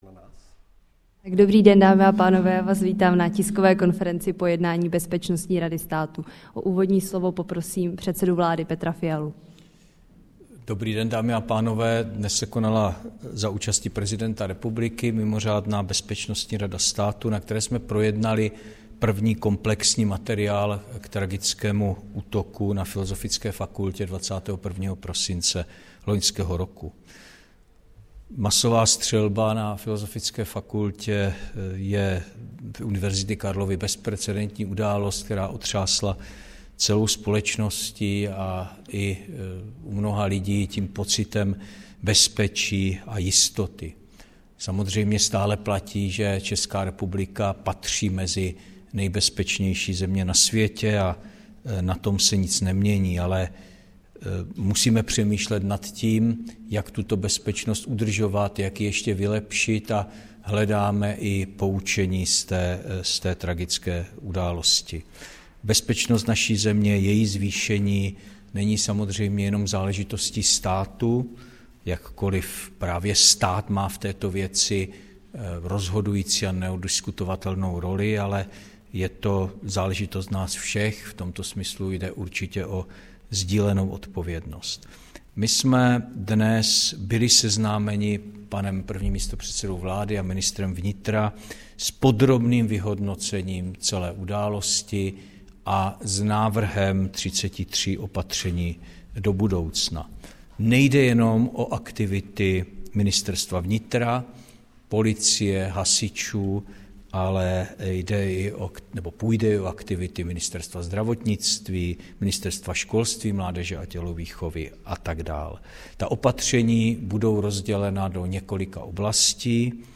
Tisková konference po mimořádném zasedání Bezpečnostní rady státu, 31. ledna 2024